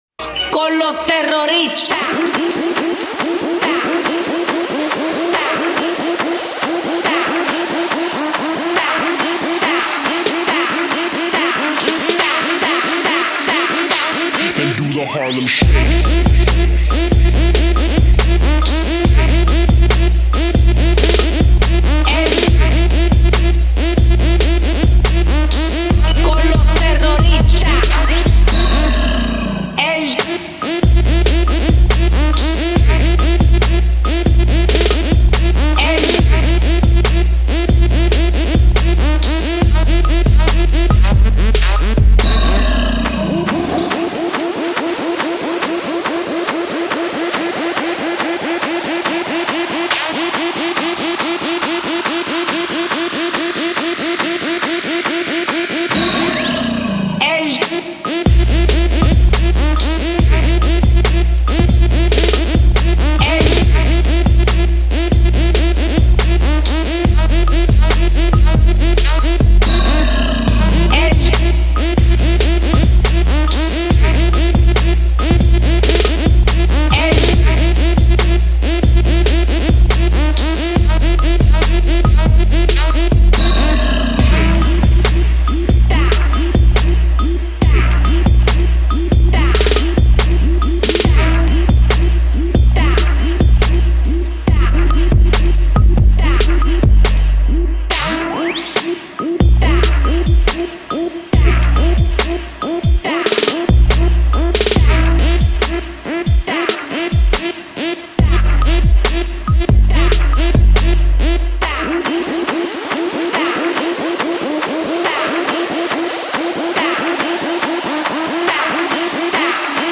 EDM Disco Hip hop music